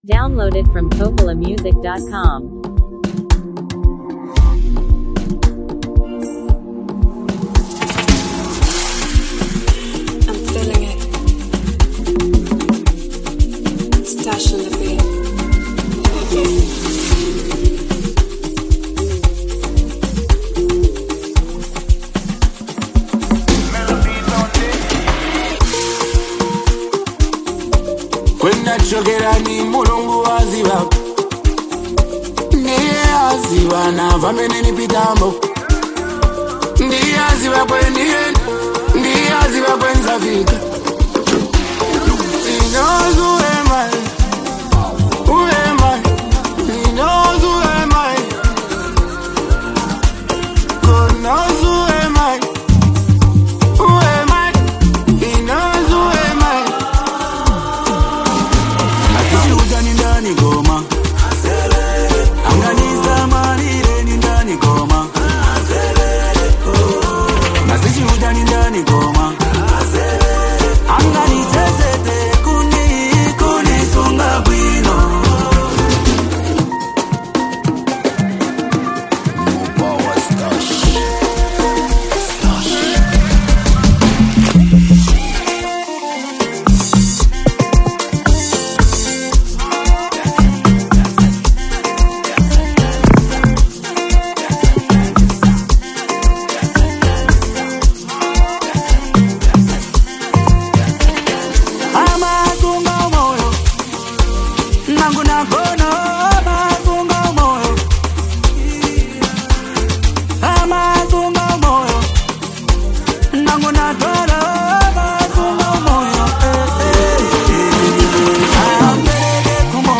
who add a soulful touch.